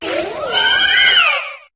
Cri de Florges dans Pokémon HOME.